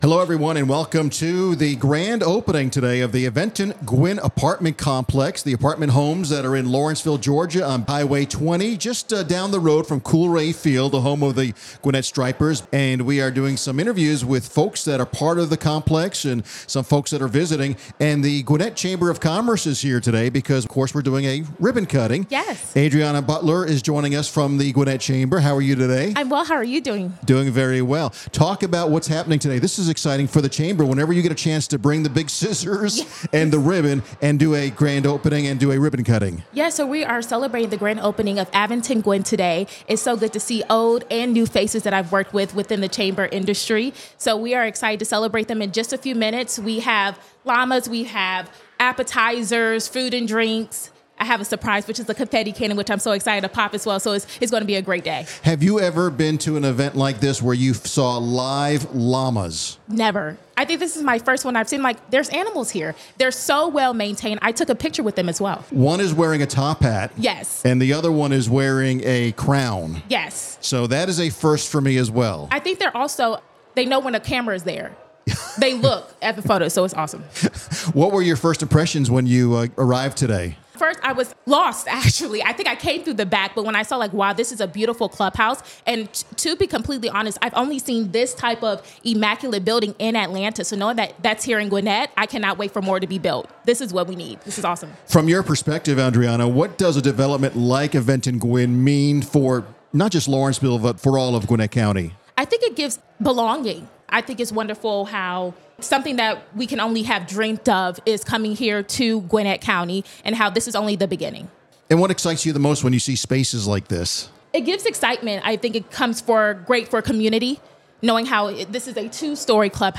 Business RadioX was on site to interview property management and staff, residents, and business professionals attending the event.